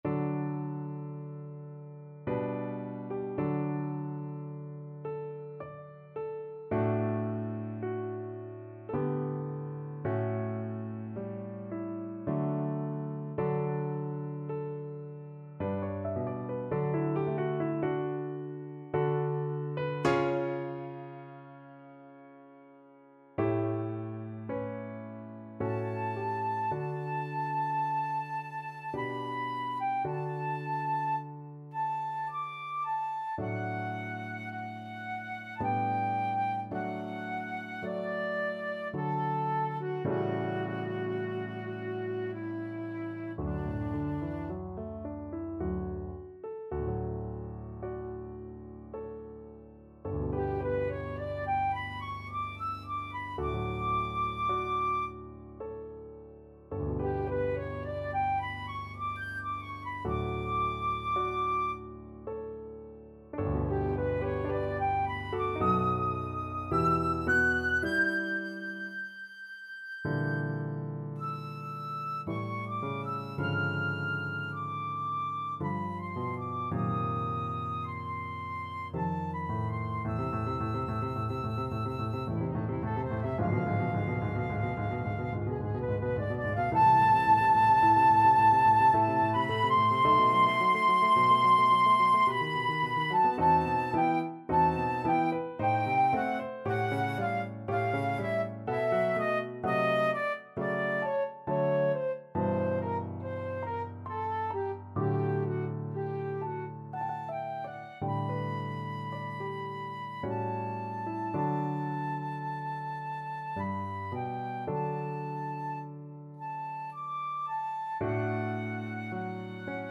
Flute
D major (Sounding Pitch) (View more D major Music for Flute )
Adagio ma non troppo =108
3/4 (View more 3/4 Music)
Classical (View more Classical Flute Music)
dvorak_cello_concerto_2nd_mvt_FL.mp3